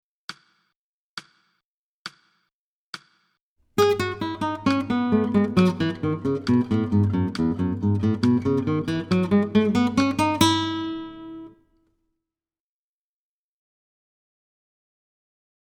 String Bass
Piano Accompaniment